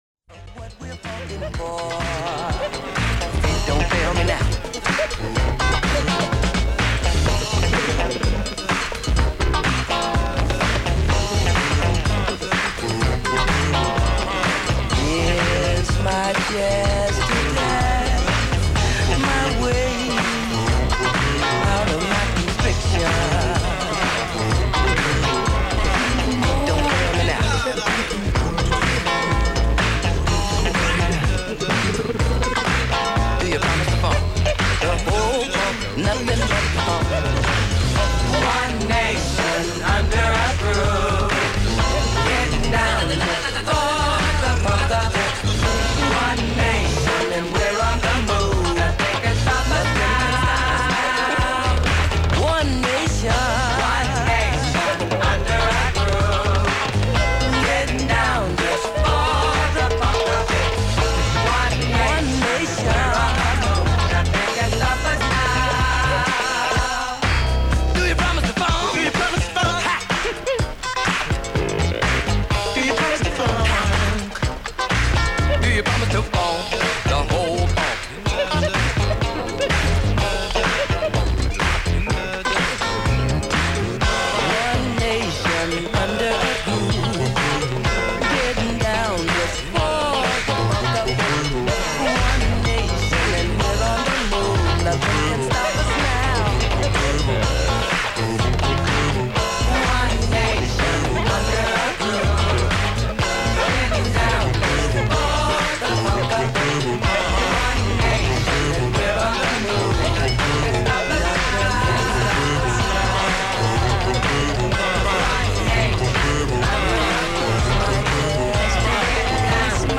This is an old school set for the books.